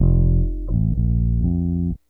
BASS 22.wav